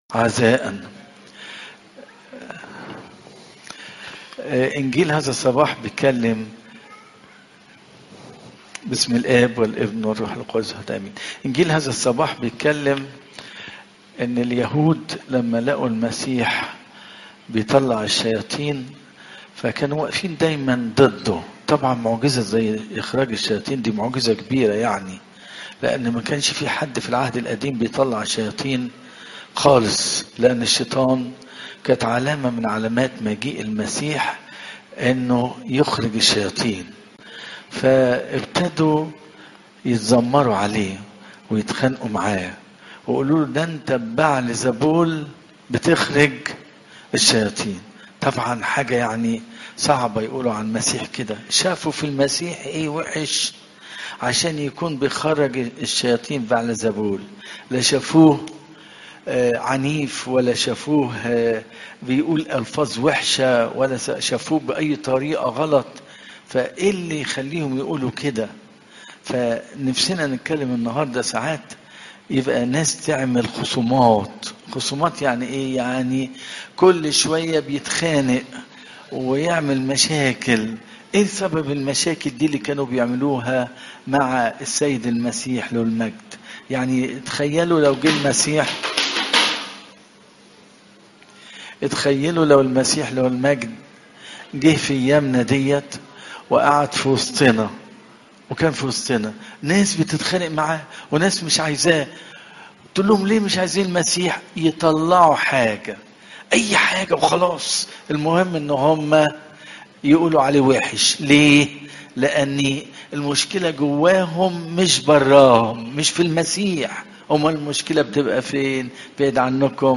عظات قداسات الكنيسة (مر 3 : 28 - 35)